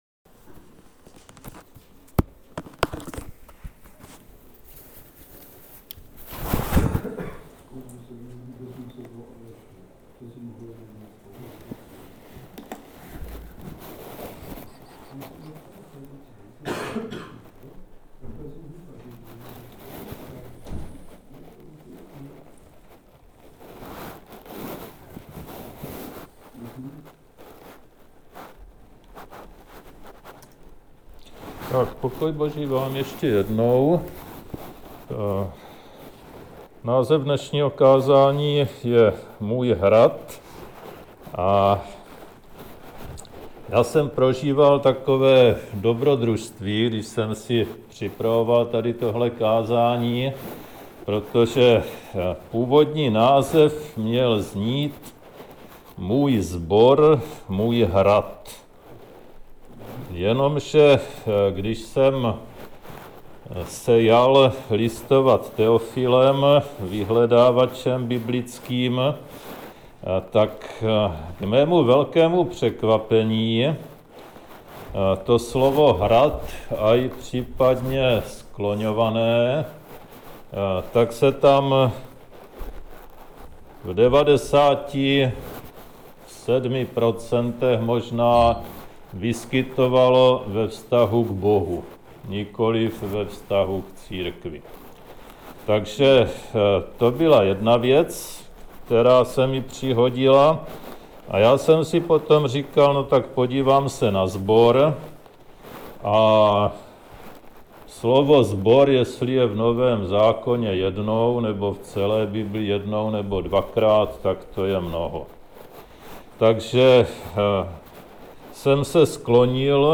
Kázání
Omlouváme se za zhoršenou kvalitu záznamu, jedná se o testovací záznam novým způsobem, který umožní rychleji dostat nahrávky na web.